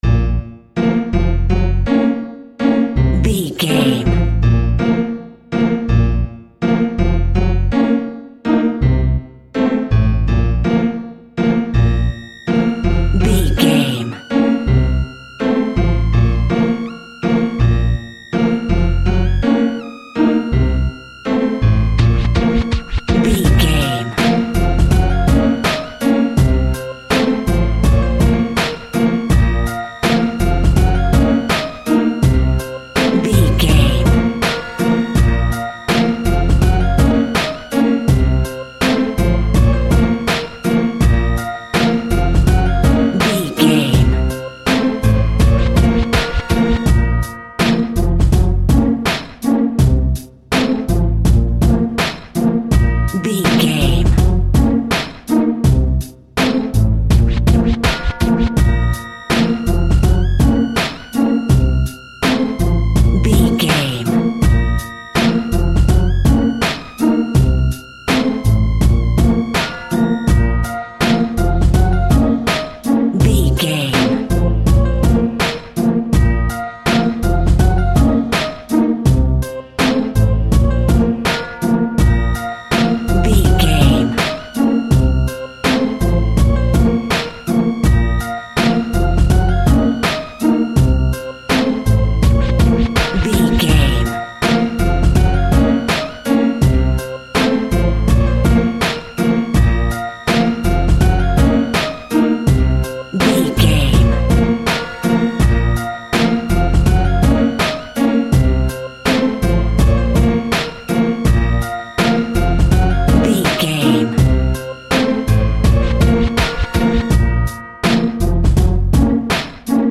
Halloween Hip Hop Fusion.
In-crescendo
Aeolian/Minor
ominous
eerie
Horror Synths